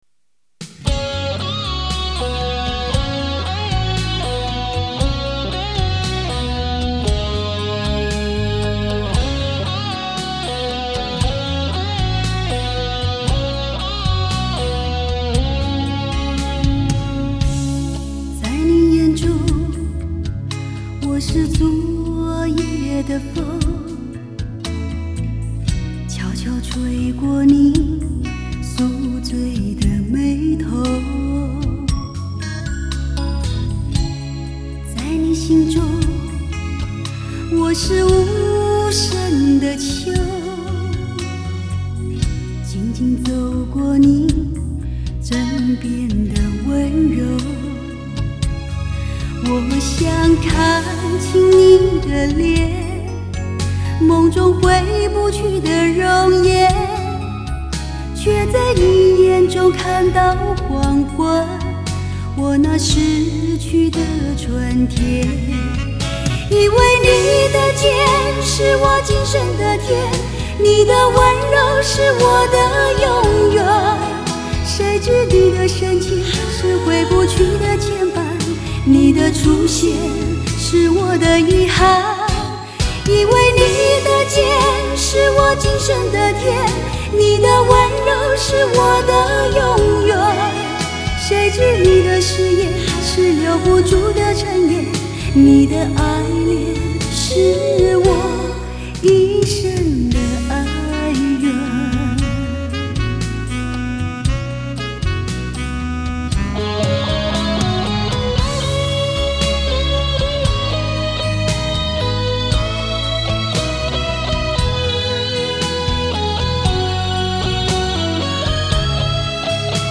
44.100 Hz;16 Bit;立体声